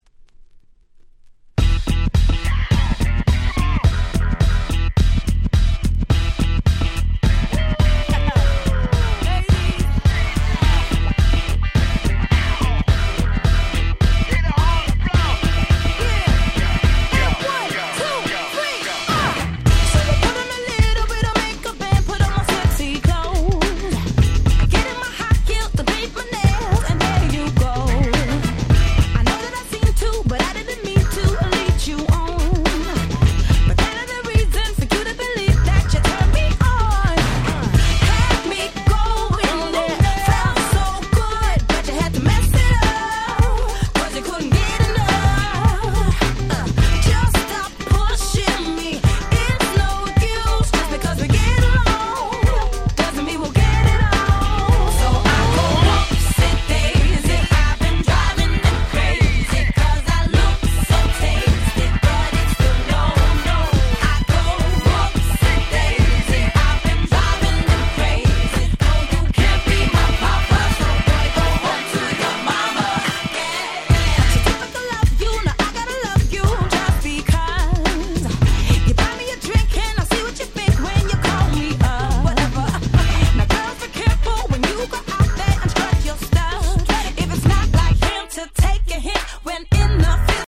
04' Very Nice UK R&B !!
今聴いても全く古さを感じさせません！！